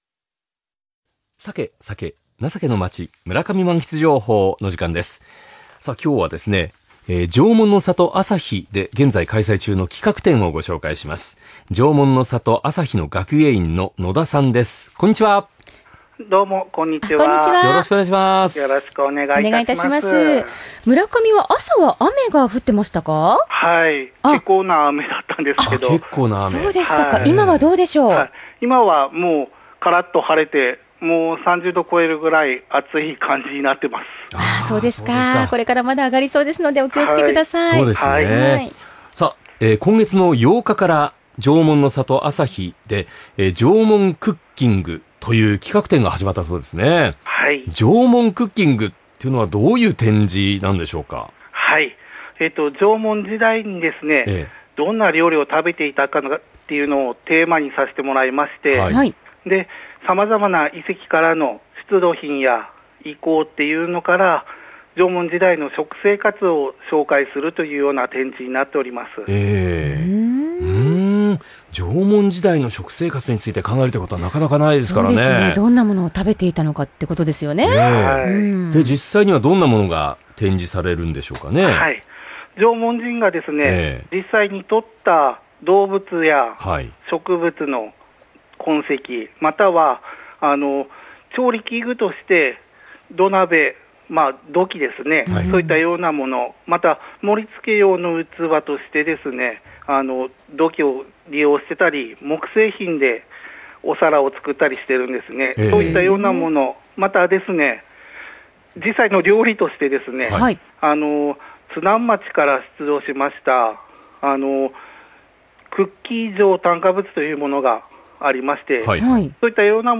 生放送   ２０２０年８月１３日（木）